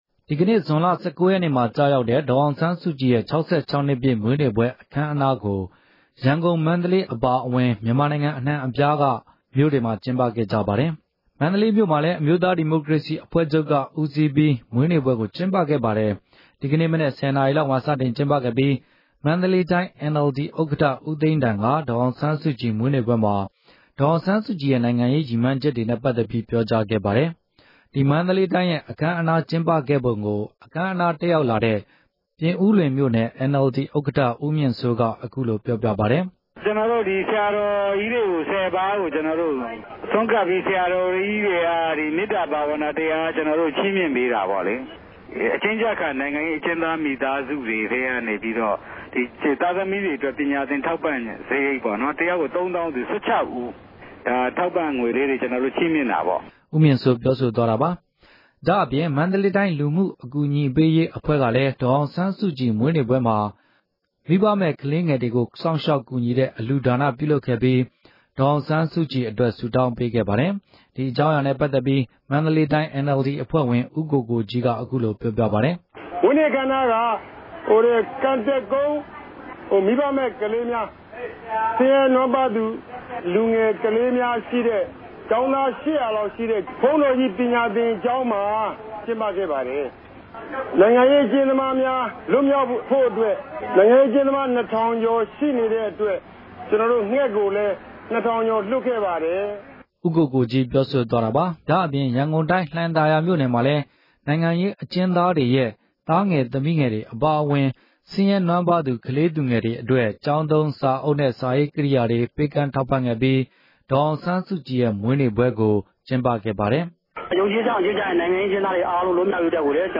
RFA သတင်းထောက်တွေက စုစည်းတင်ပြ ထားပါတယ်။